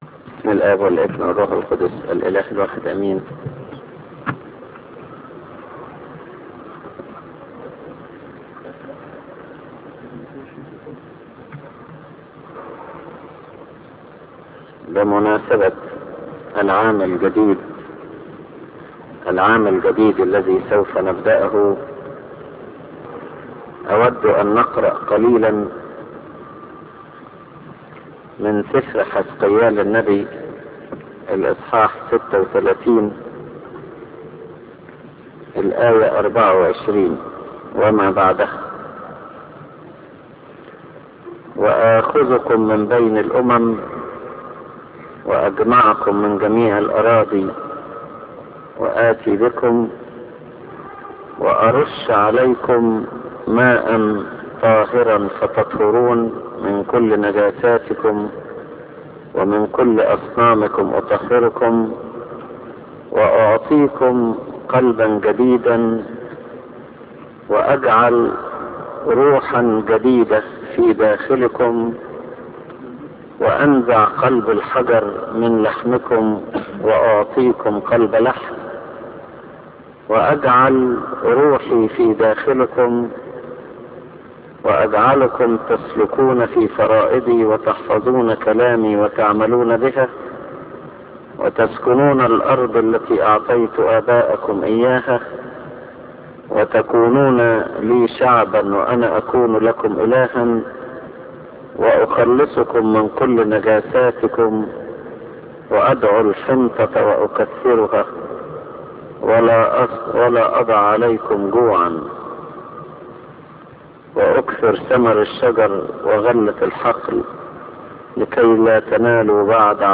Sermons1975